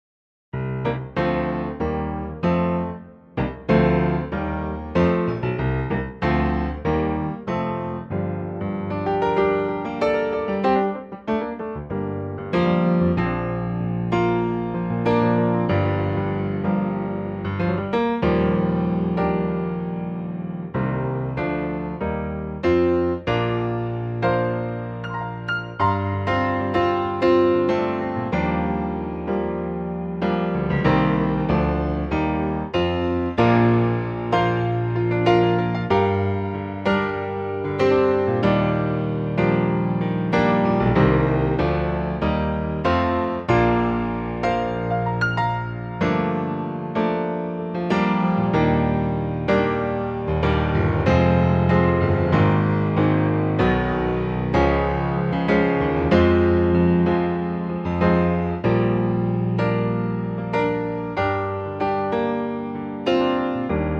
Bb Piano
key - Bb - vocal range - G to D (optional F top notes)
Here is a piano only arrangement.